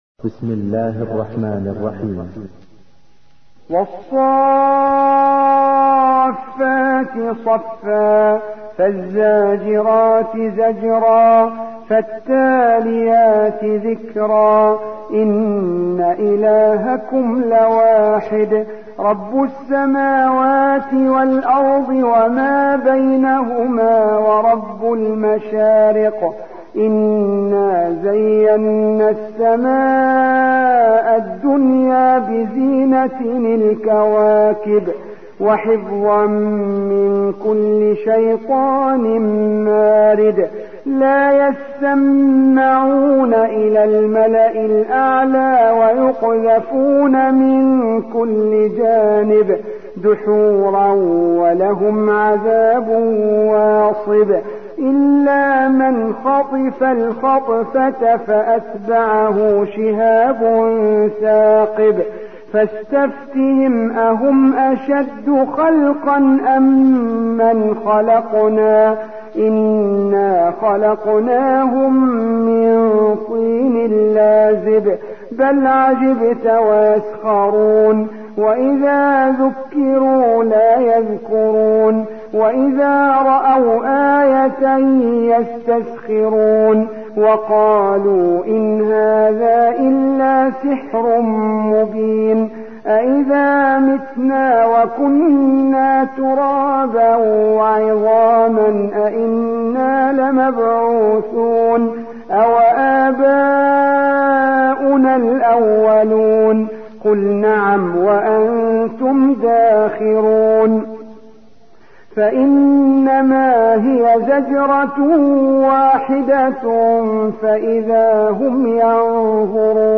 37. سورة الصافات / القارئ